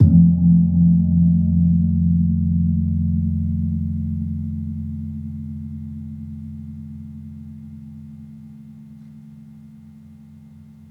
Gong-F2-f-p.wav